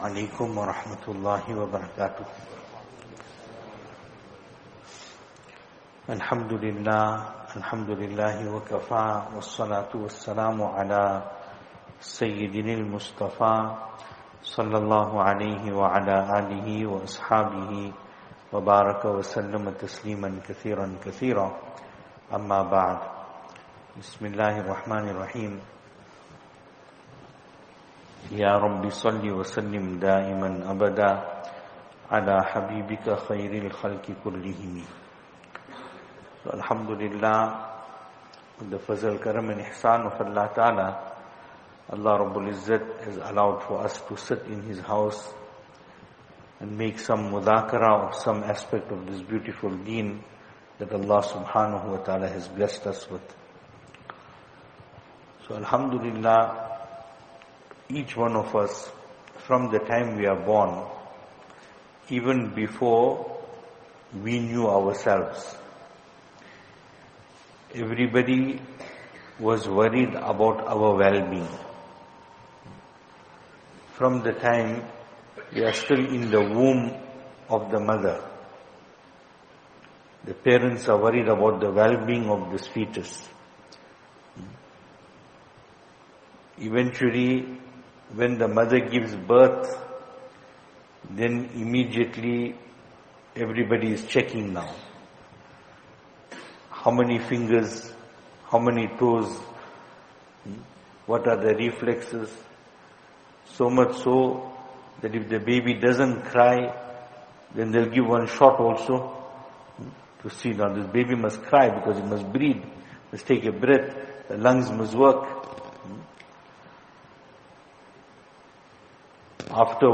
Programme from Saaberie Jumma Musjid